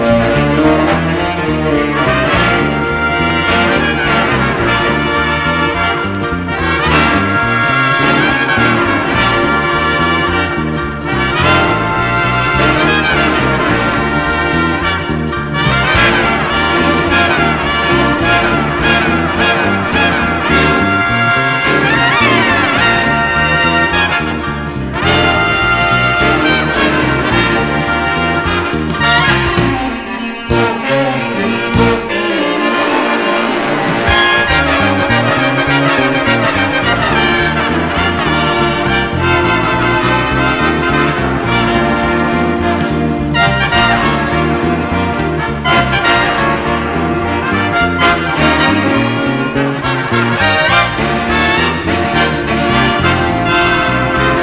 JAZZ / JAZZ VOCAL
エレピが転がり軽やかにスウィングする名カヴァーで男の色気たっぷりの歌声も超スウィート。